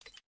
sound_switch.mp3